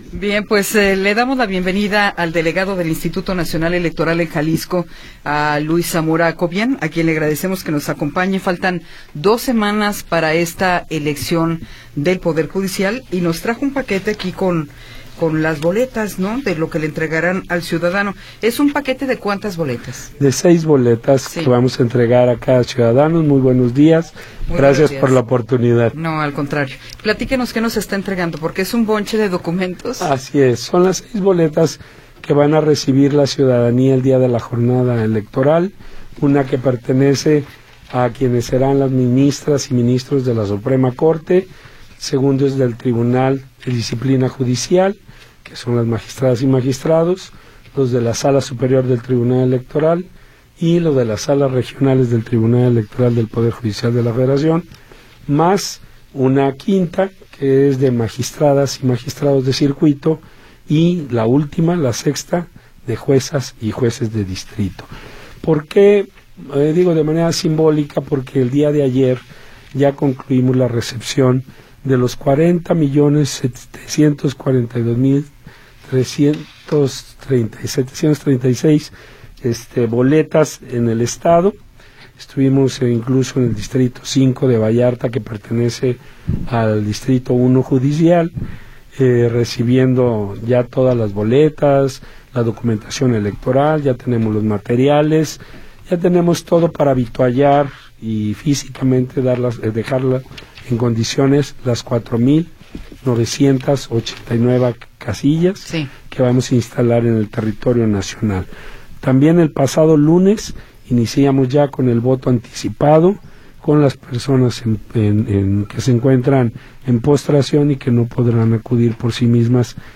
Entrevista con Luis Zamora Cobián